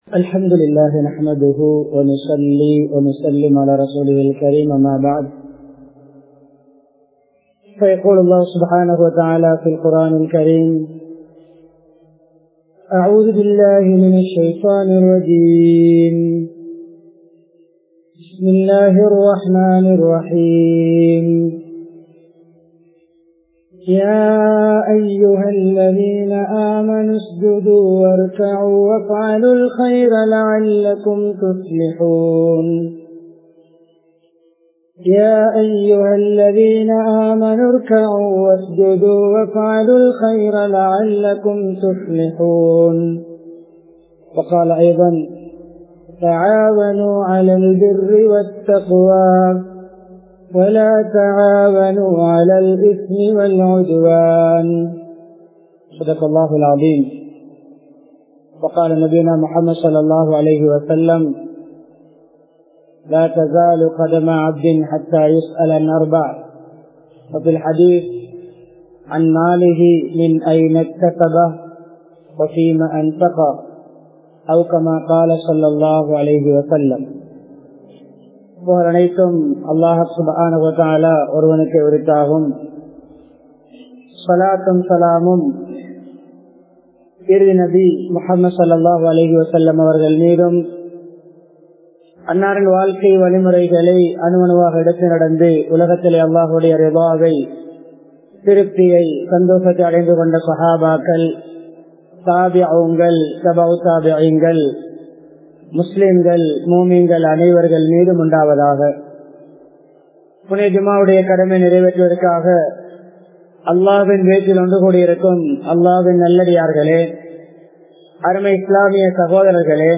மஸ்ஜிதும் சமூகமும் | Audio Bayans | All Ceylon Muslim Youth Community | Addalaichenai